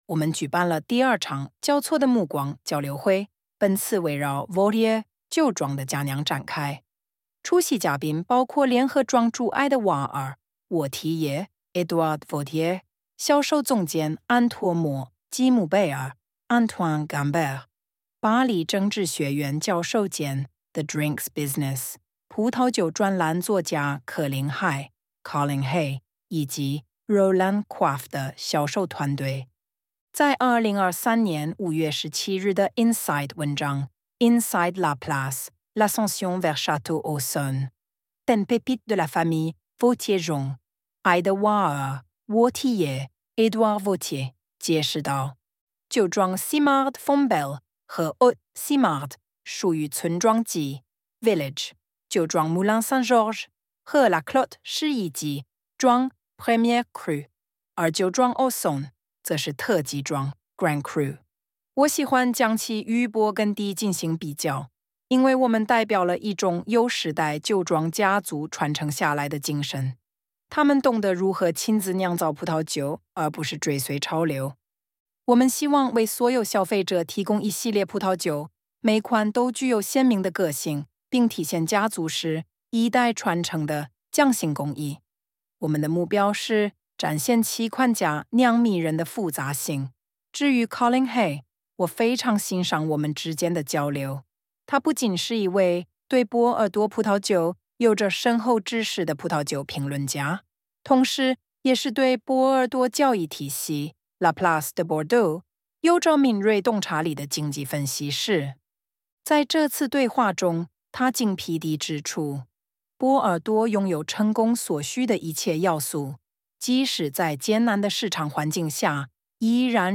ElevenLabs_texte_chinois_pour_audio.docx.mp3